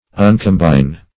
Meaning of uncombine. uncombine synonyms, pronunciation, spelling and more from Free Dictionary.
Search Result for " uncombine" : The Collaborative International Dictionary of English v.0.48: Uncombine \Un`com*bine"\, v. t. [1st pref. un- + combine.]
uncombine.mp3